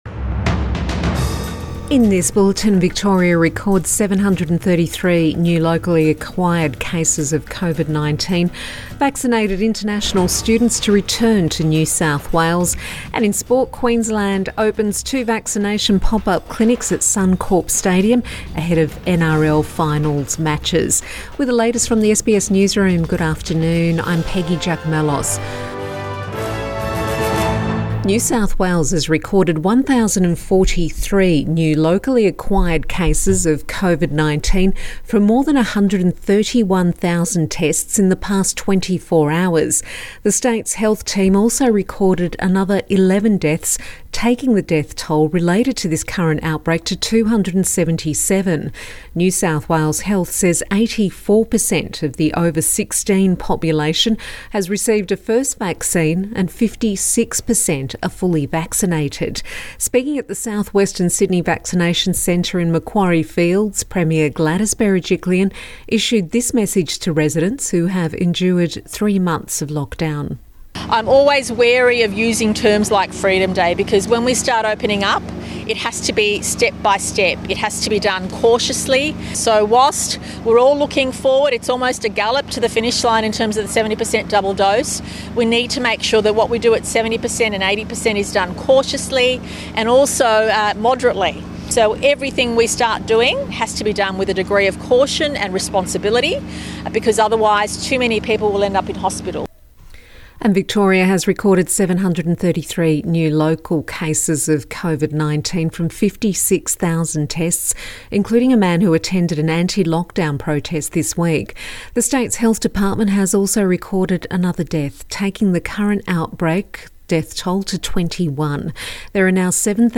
Midday bulletin September 24 2021